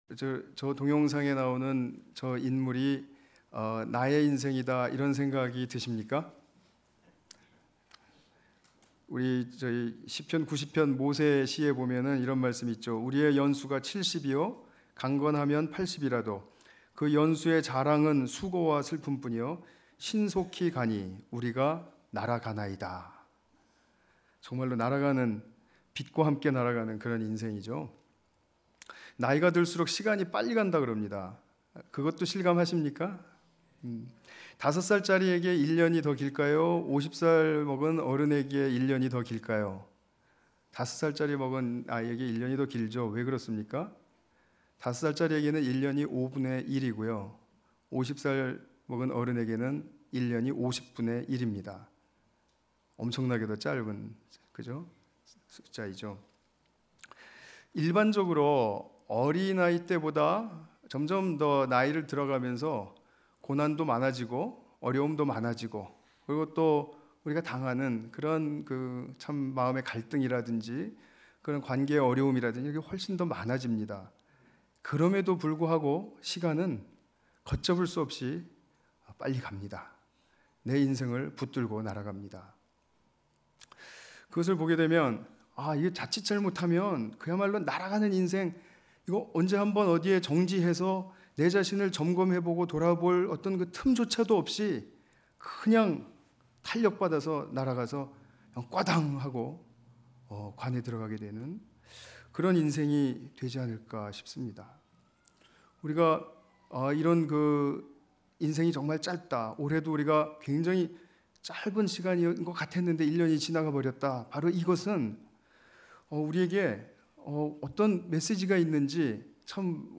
<송구영신> 영적 길갈 (Spiritual Gilgal)로 돌아가라
특별예배